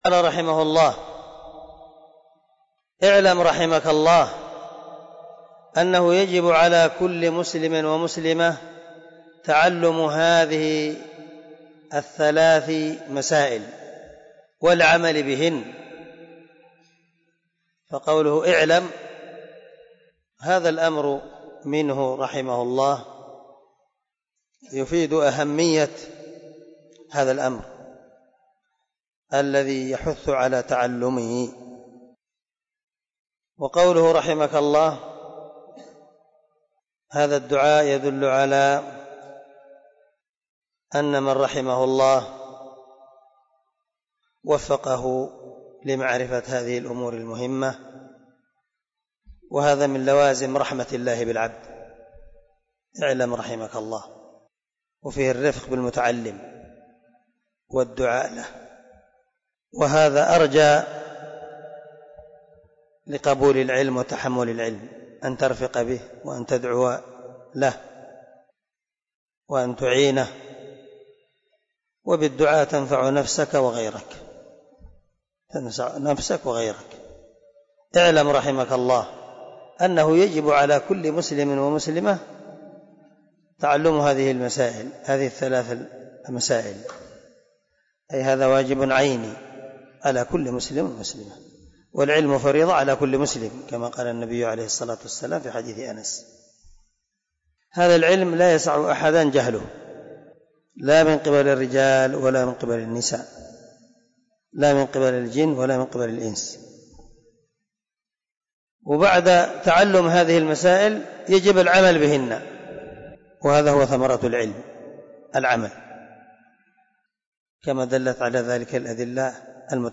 🔊 الدرس 4 من شرح الأصول الثلاثة